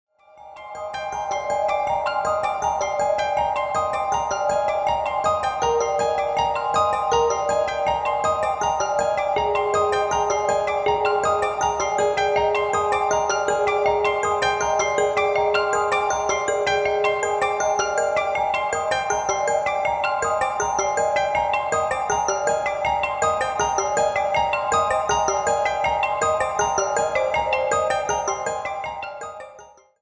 繊細で清らかなメロディーが幻想的な世界を織り成していく